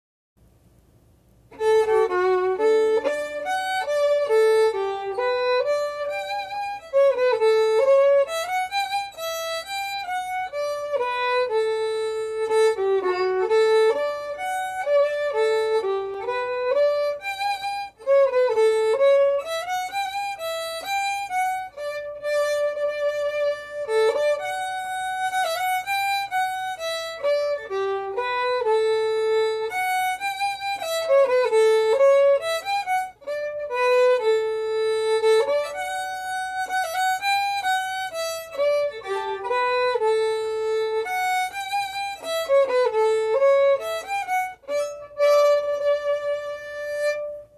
Key: D
Form: Waltz
Played slowly for learning
M: 3/4